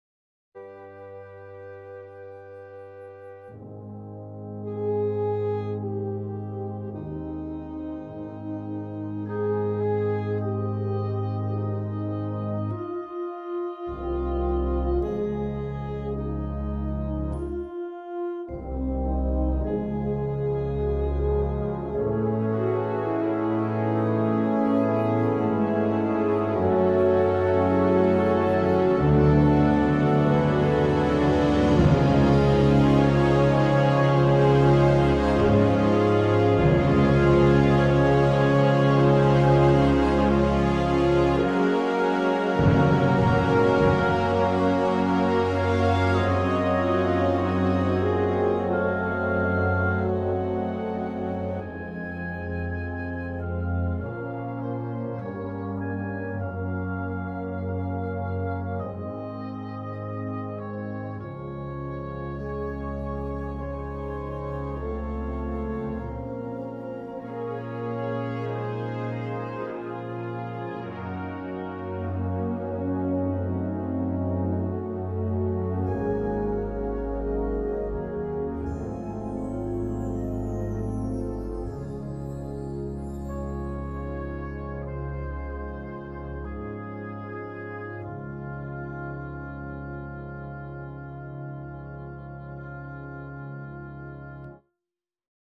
concert band piece